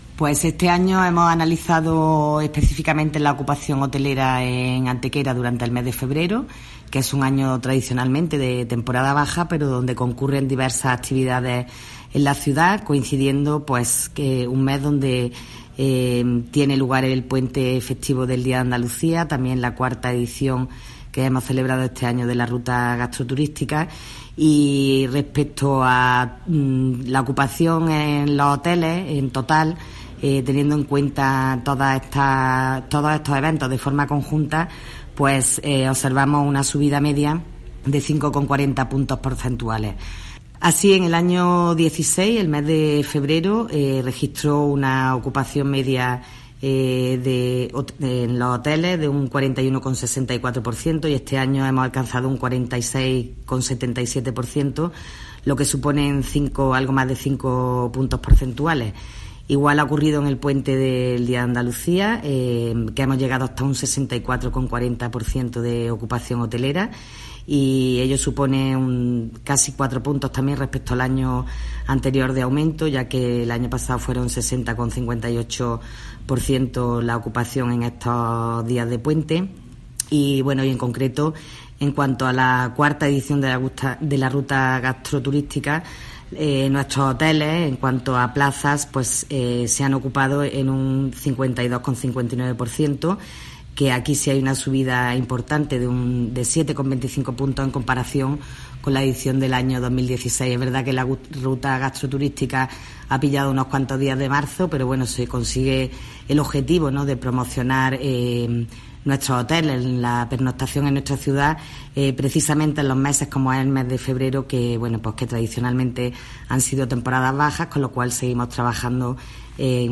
Cortes de voz B. Jiménez 931.43 kb Formato: mp3